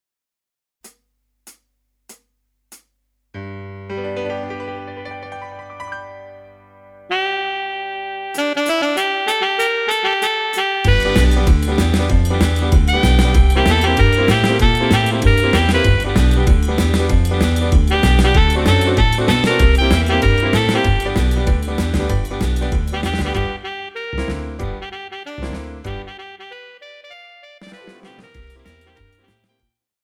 Žánr: Rock And Roll
BPM: 96
Key: G
MP3 ukázka s ML